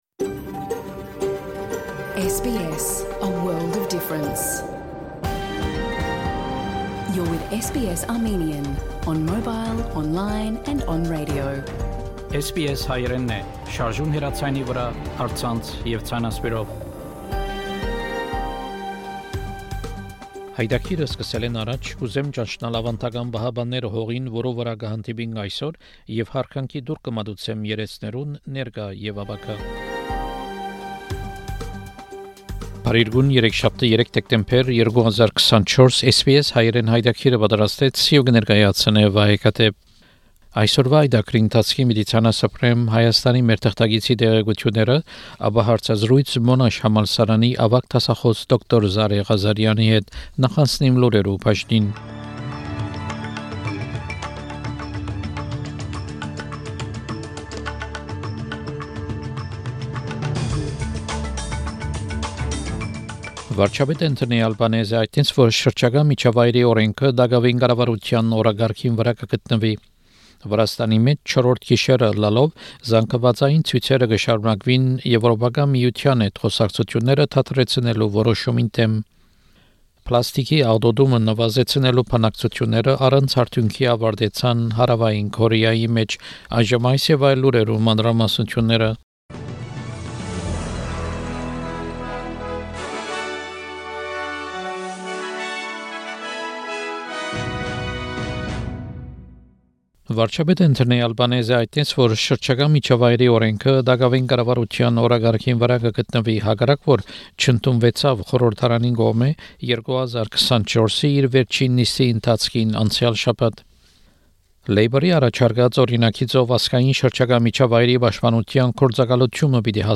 SBS Հայերէնի աւստրալիական և միջազգային լուրերը քաղուած 3 Դեկտեմբեր 2024 յայտագրէն: SBS Armenian news bulletin from 3 December program.